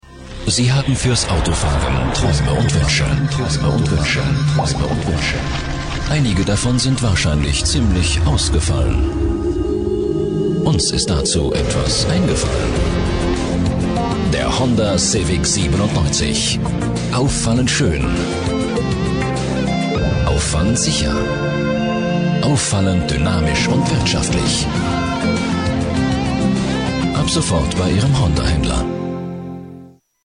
Prägnante tiefe Stimme mit großer Variationsbreite
Sprechprobe: Werbung (Muttersprache):
Great deep German voice